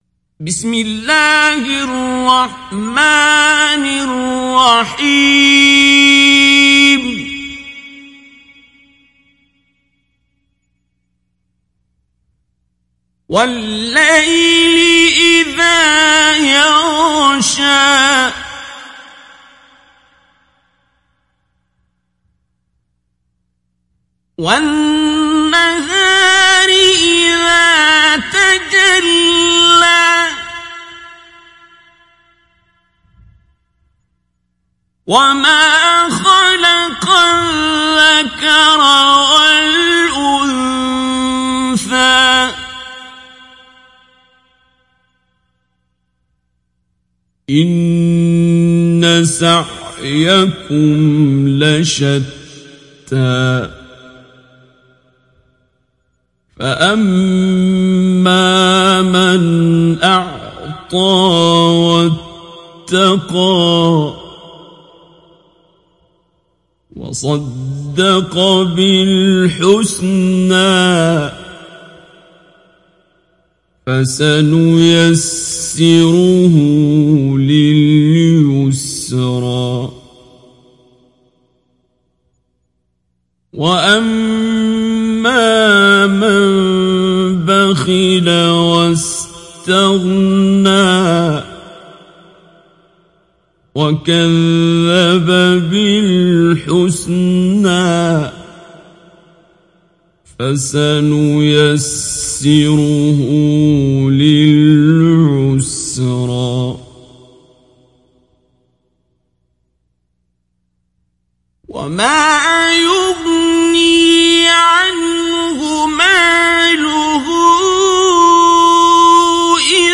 Leyl Suresi İndir mp3 Abdul Basit Abd Alsamad Mujawwad Riwayat Hafs an Asim, Kurani indirin ve mp3 tam doğrudan bağlantılar dinle
İndir Leyl Suresi Abdul Basit Abd Alsamad Mujawwad